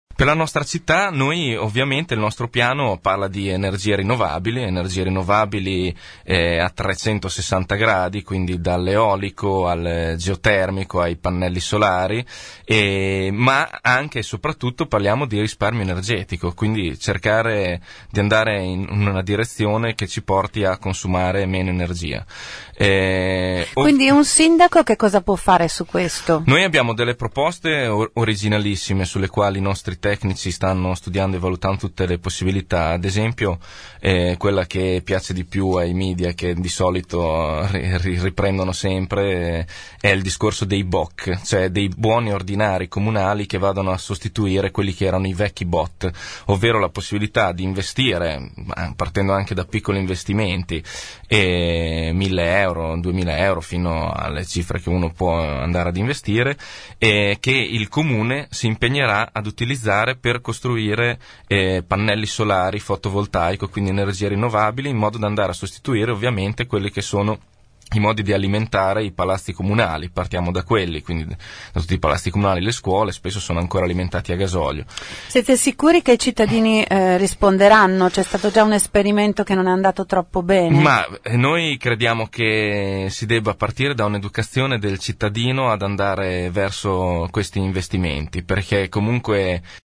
ospite dei nostri studi